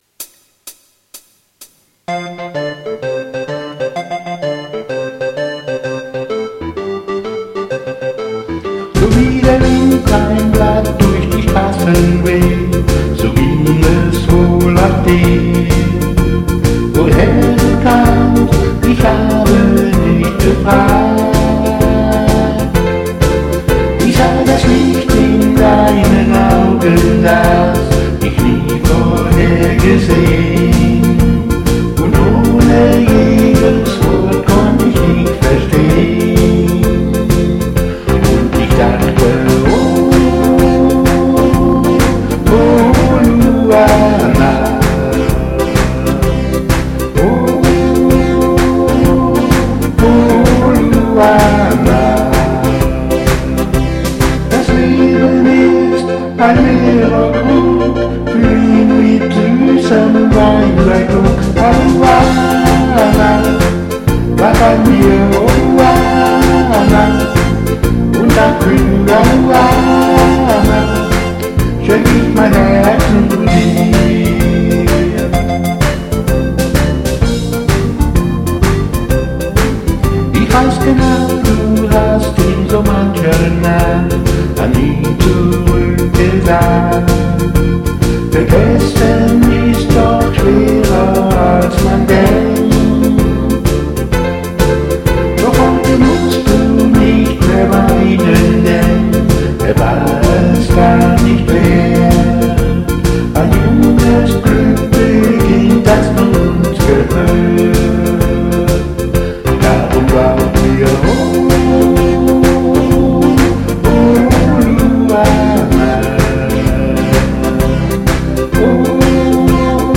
• Alleinunterhalter